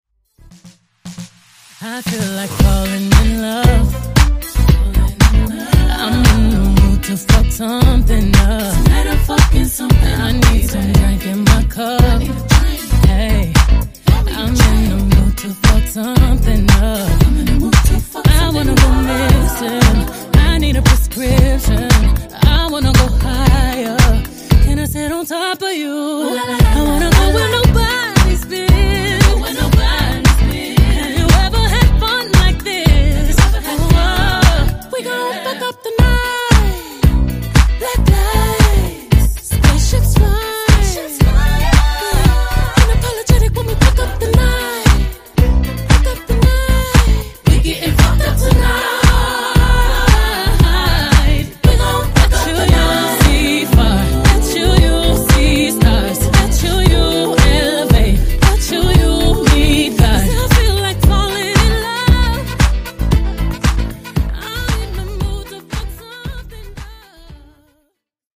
Genres: RE-DRUM , TWERK
Dirty BPM: 74 Time